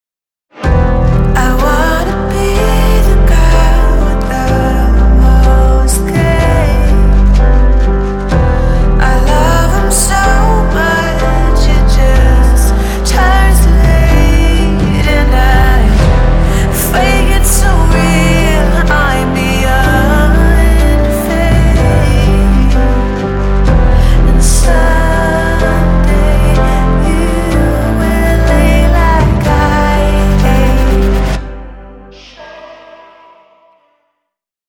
Indie Pop